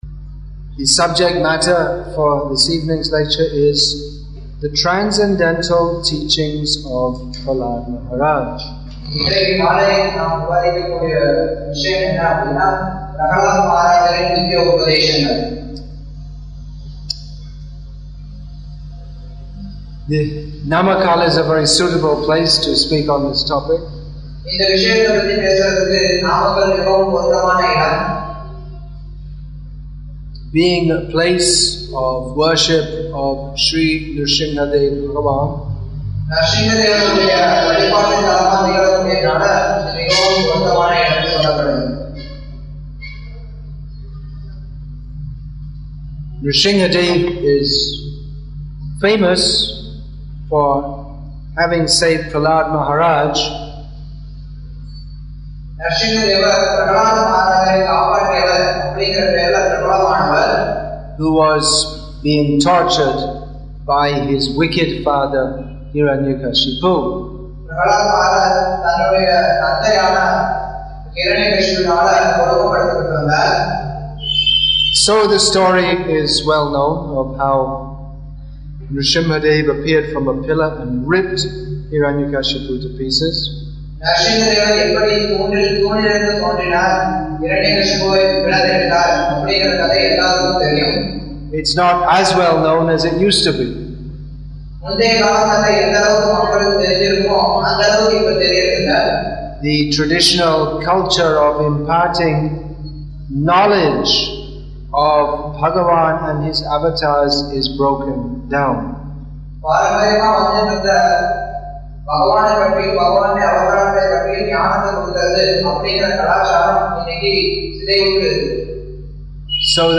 Assorted Lectures
English with தமிழ் (Tamil) Translation; Namakkal, Tamil Nadu , India